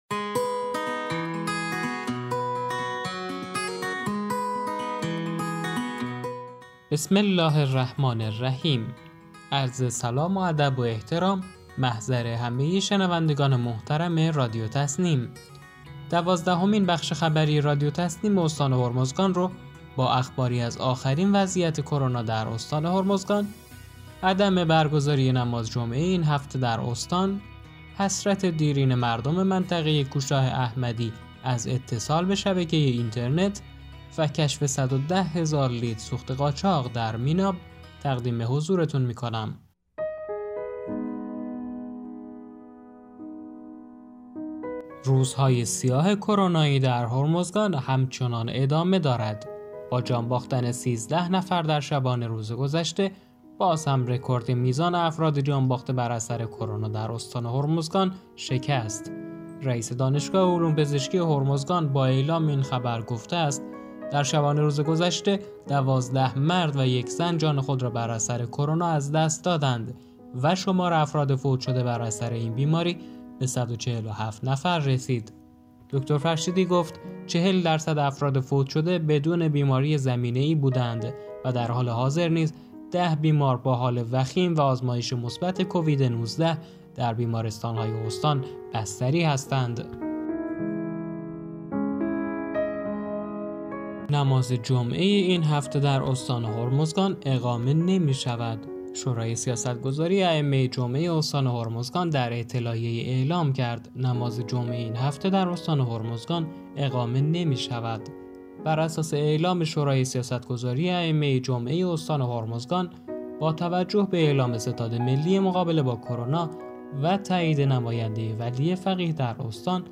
رادیو| مخابرات هرمزگان مقصر اصلی عدم اتصال کوهشاه احمدی به شبکه اینترنت - تسنیم